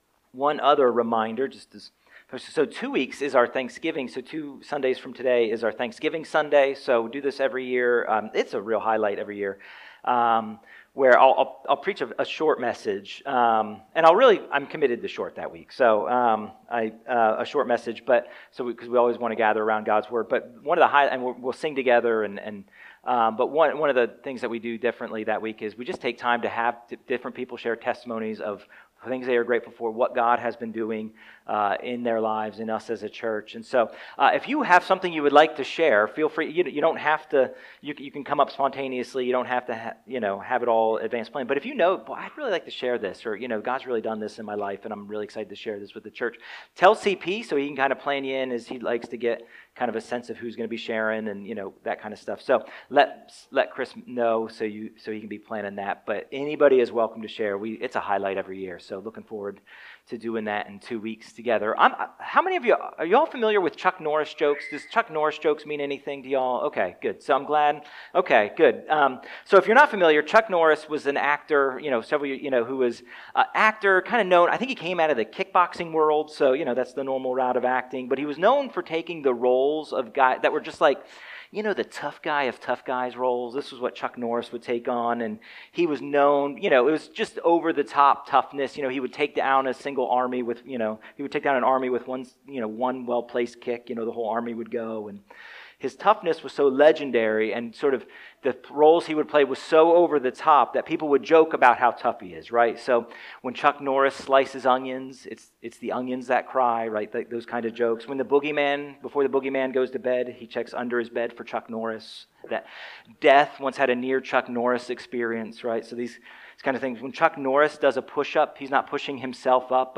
A message from the series "Higher."